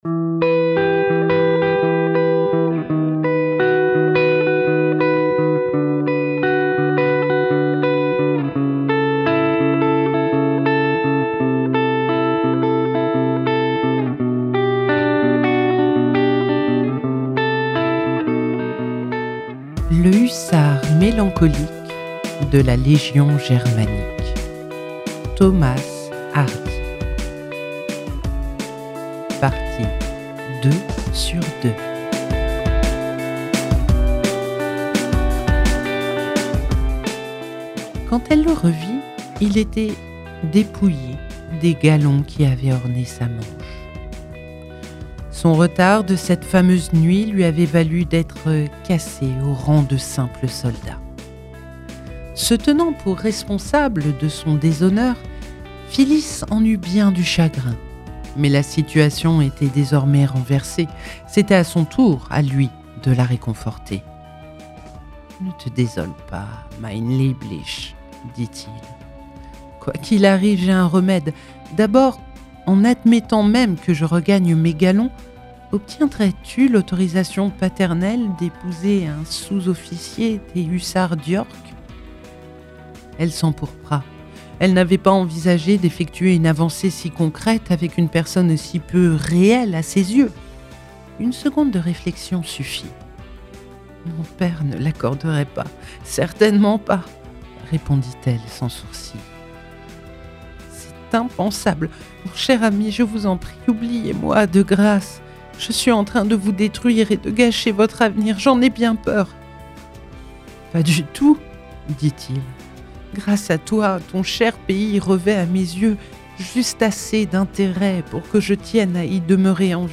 🎧 Le Hussard mélancolique de la Légion germanique – Thomas Hardy - Radiobook